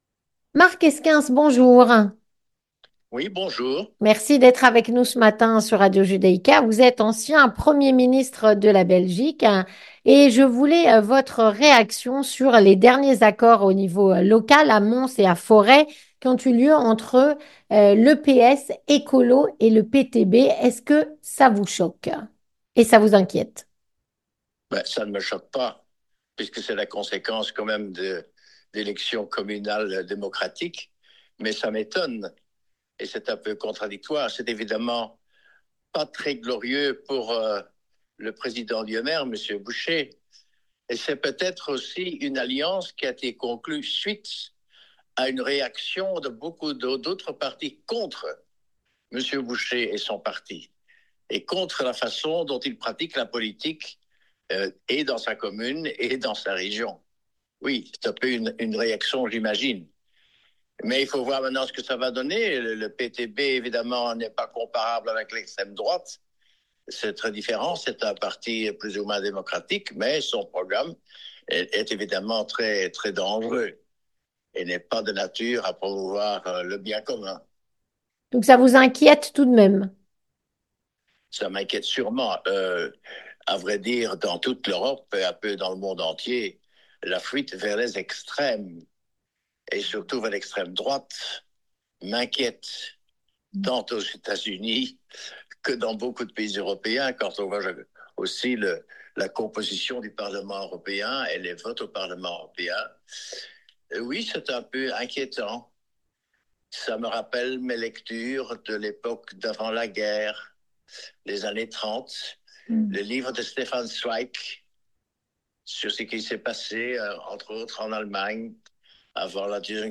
Avec Mark Heyskens, ancien premier ministre de Belgique